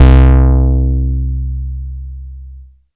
ANALOG 2 2.wav